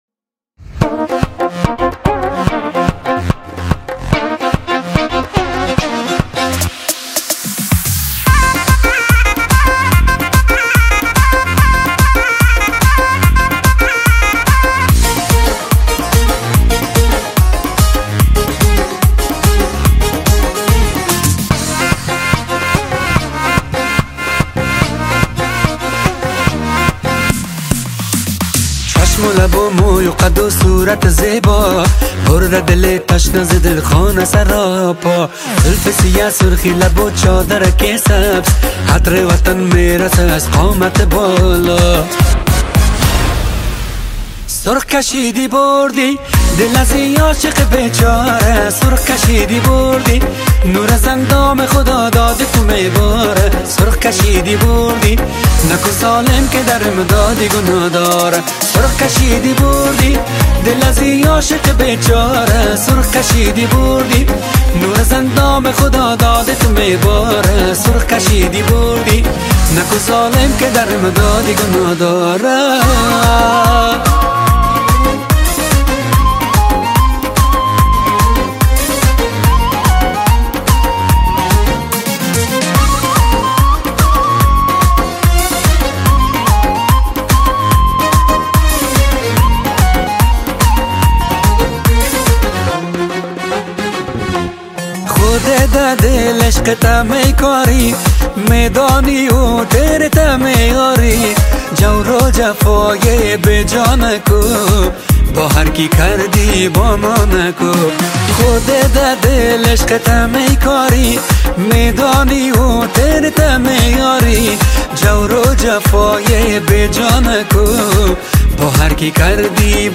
Afghan Music